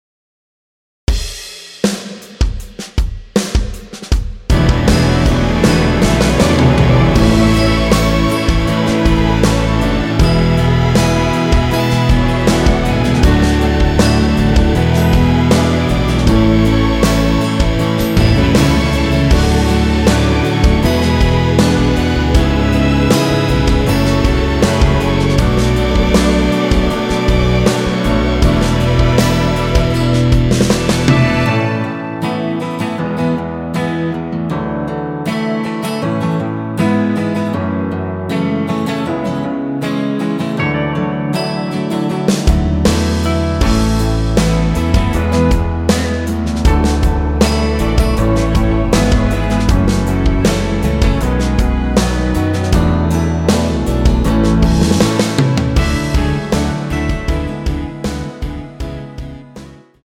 원키에서(-2)내린 MR입니다.
◈ 곡명 옆 (-1)은 반음 내림, (+1)은 반음 올림 입니다.
앞부분30초, 뒷부분30초씩 편집해서 올려 드리고 있습니다.
중간에 음이 끈어지고 다시 나오는 이유는